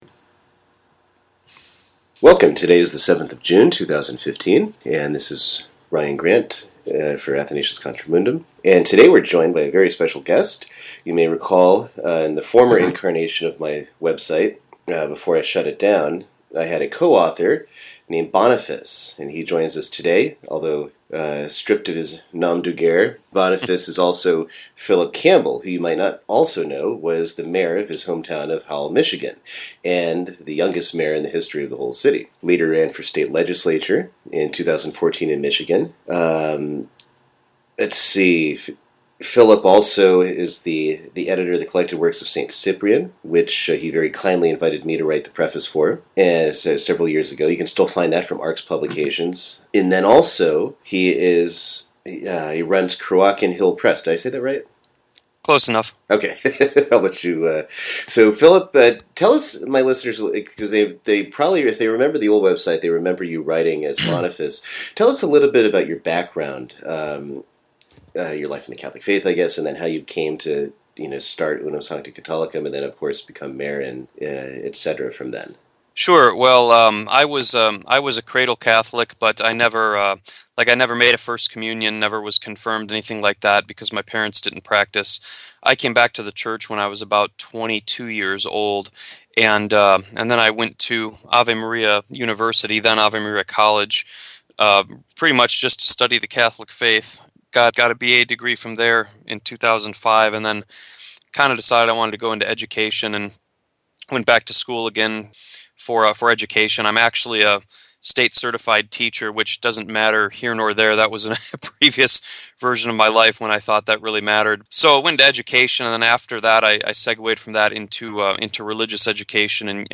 interview15_boniface_being_mayor_in_small_town.mp3